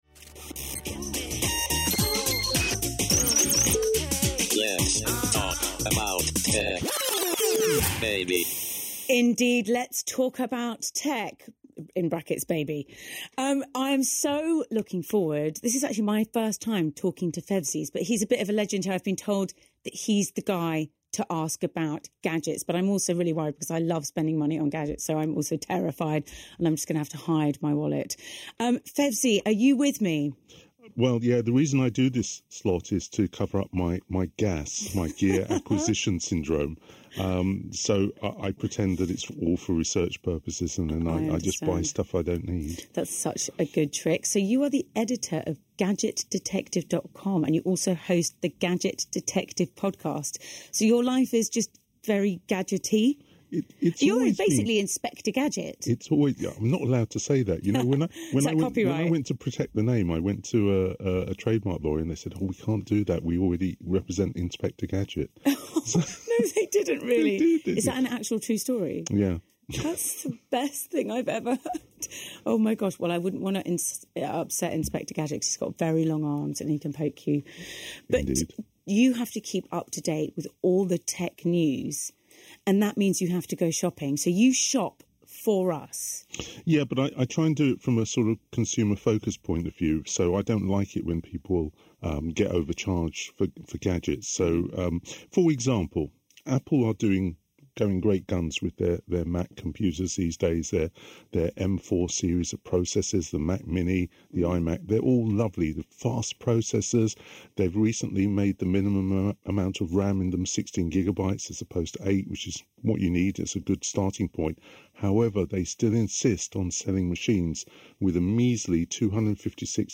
29th May 2025 - The latest Tech Reviews on BBC Radio London